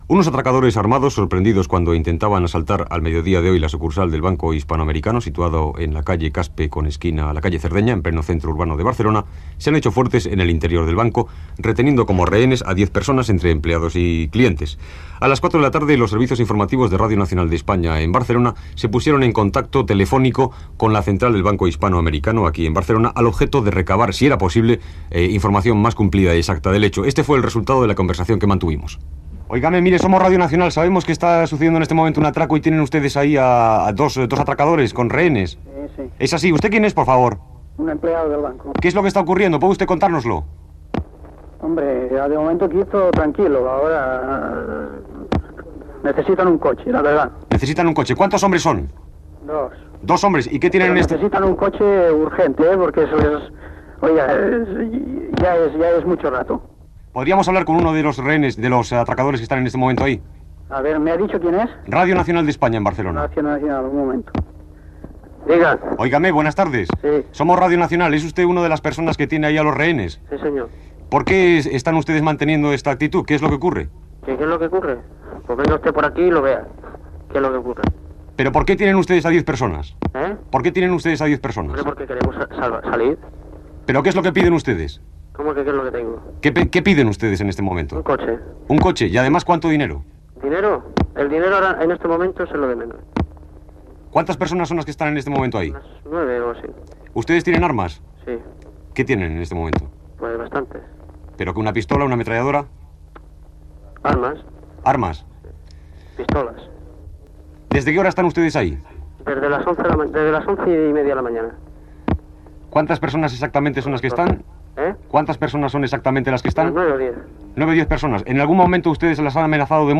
Informatiu